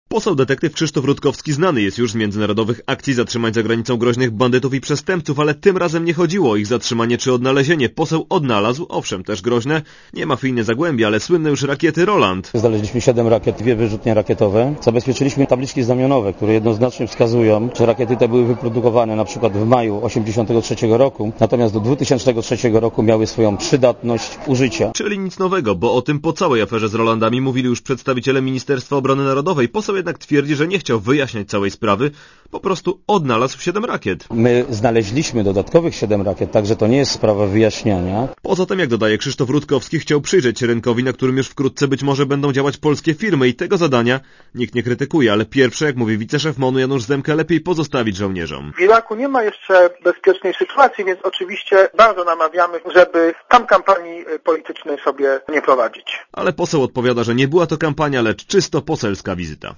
Komentarz audio (236Kb)